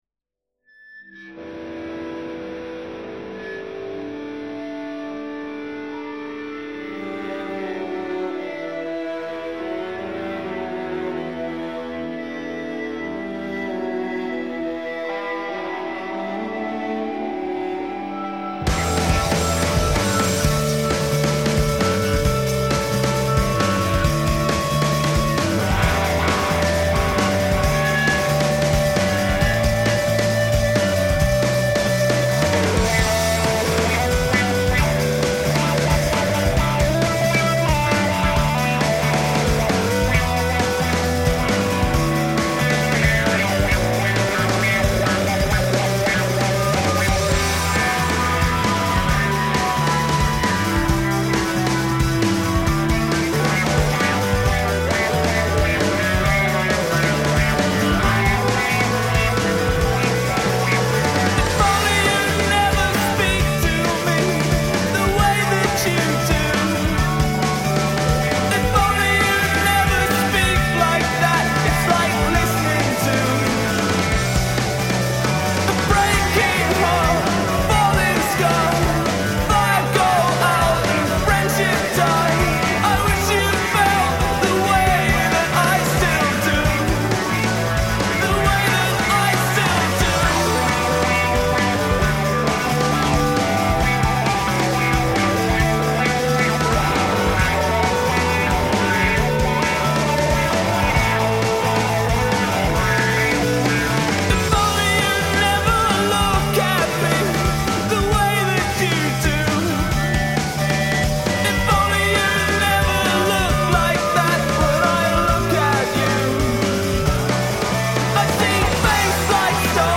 de treurige afscheidsliedjes